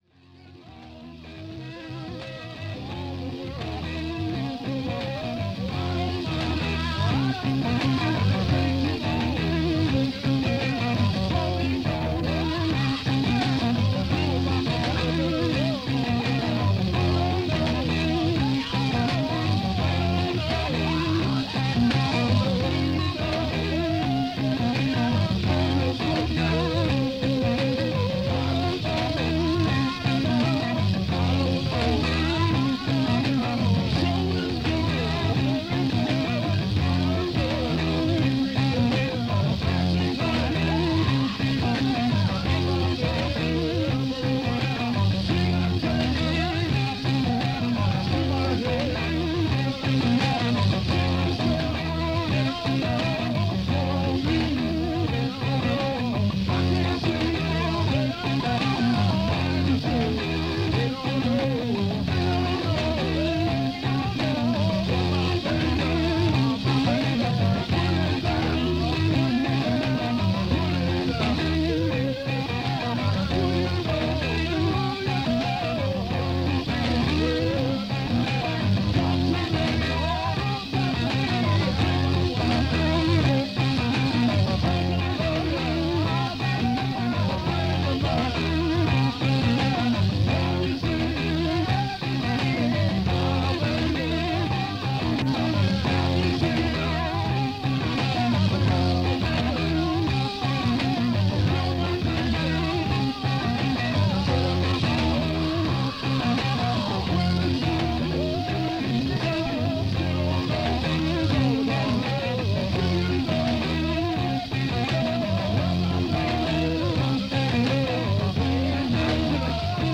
Rehearsals 1969